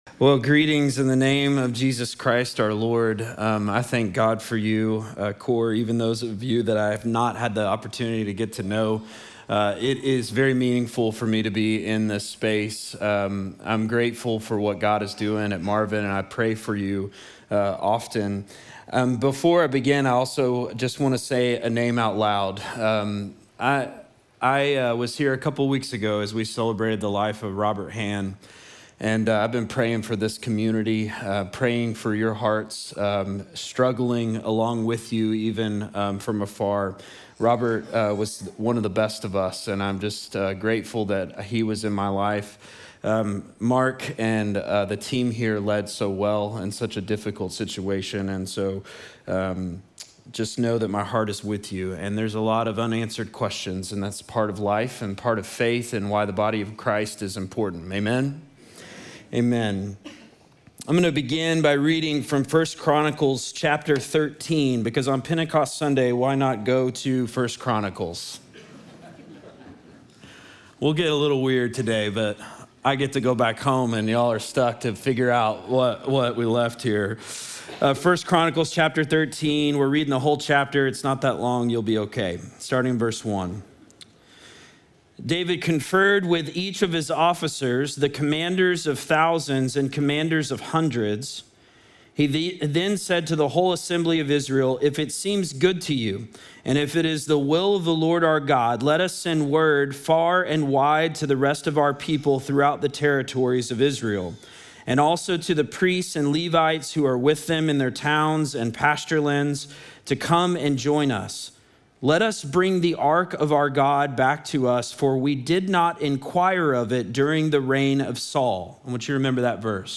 Sermon text: 1 Chronicles 13